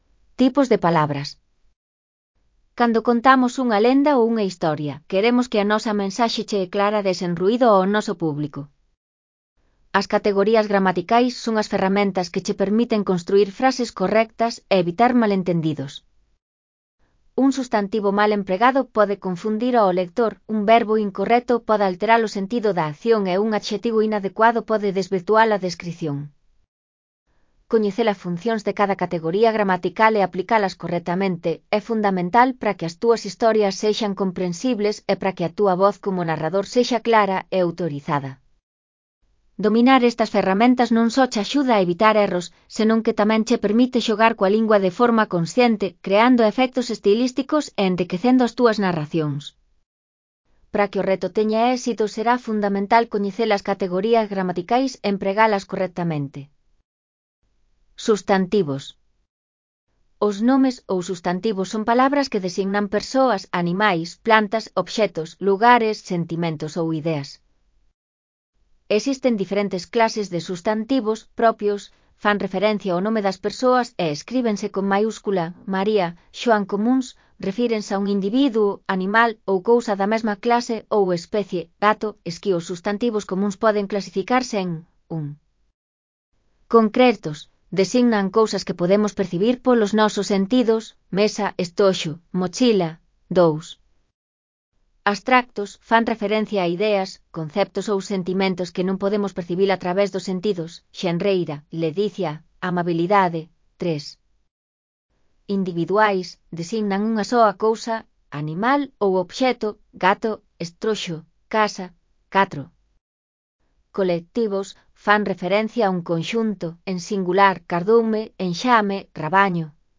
Lectura facilitada
Elaboración propia (proxecto cREAgal) con apoio de IA voz sintética xerada co modelo Celtia. Tipos de palabras (CC BY-NC-SA)